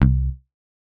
Index of /RoBKTA Sample Pack Supreme/BASSES